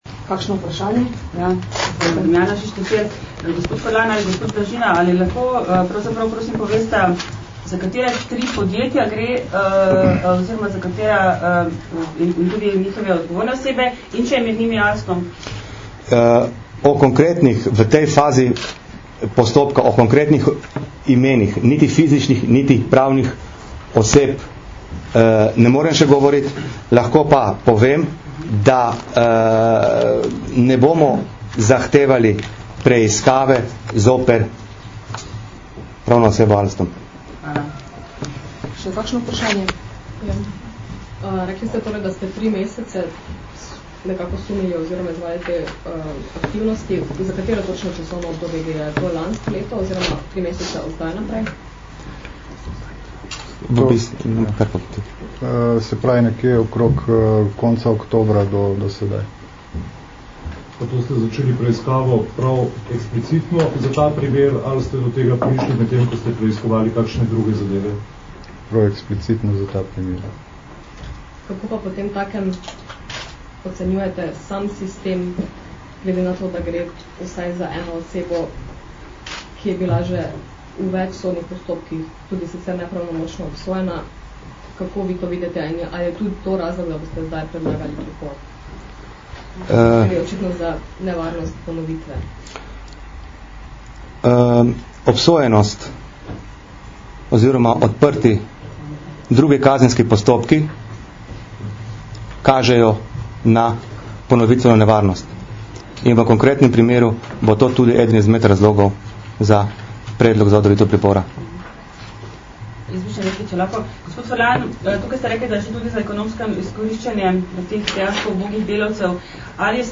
Zvočni posnetek novinarskih vprašanj in odgovorov (mp3)